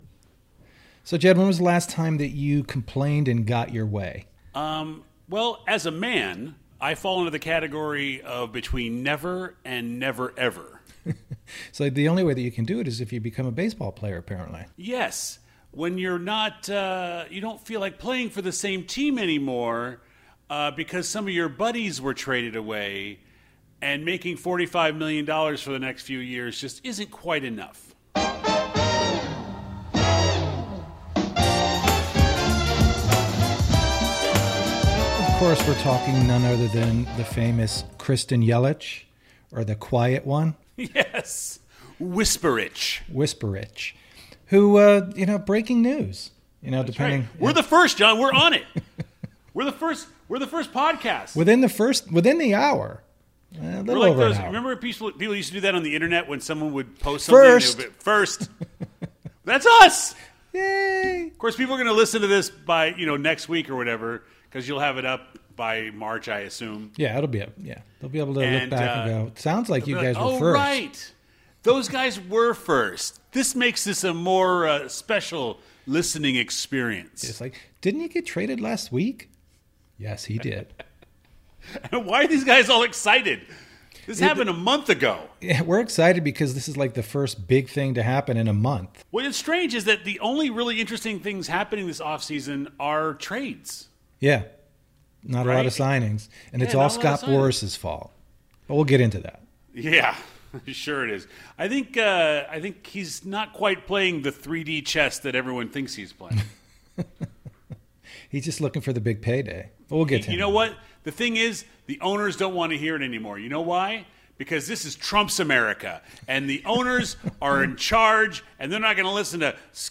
First and foremost, Christian Yelich was traded mere minutes before we recorded Episode 53; thus, the extra pep in our voices.